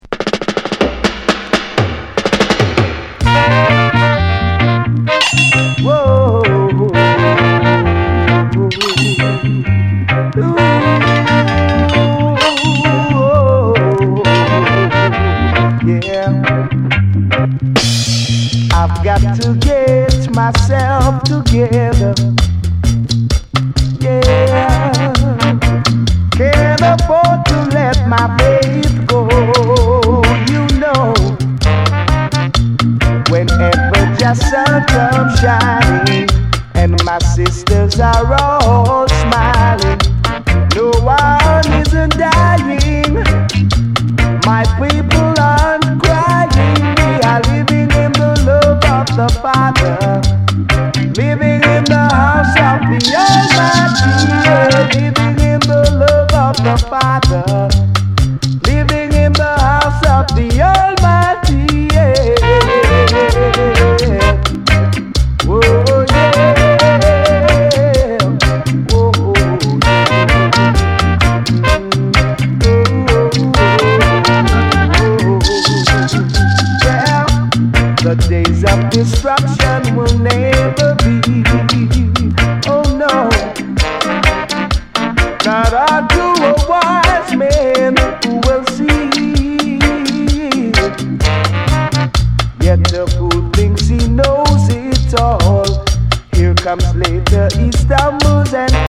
スウィートな中にも熱さが光る素晴らしい内容です。